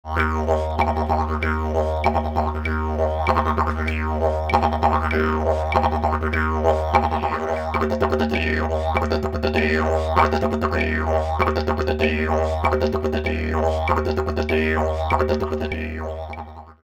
Practice Rhythms to Develop Your Technique
CLICK HERE fast triple tongue rhythm